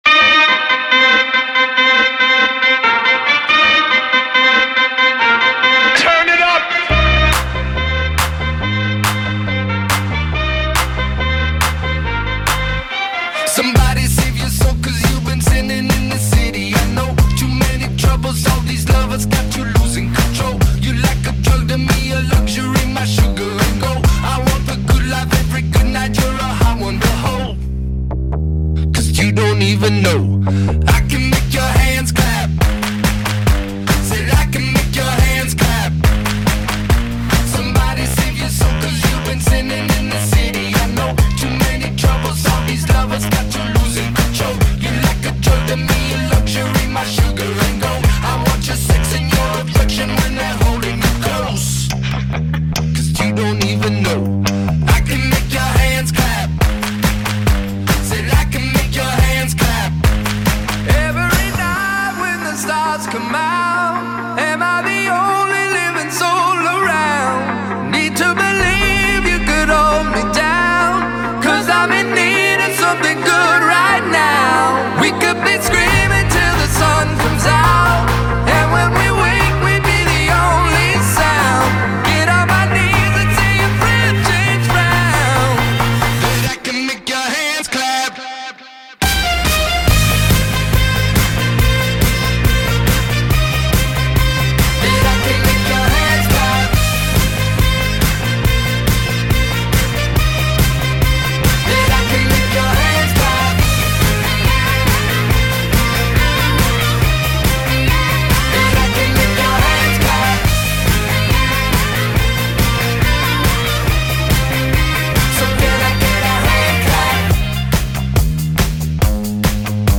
BPM136-140
It's a bit more dance-y towards the end.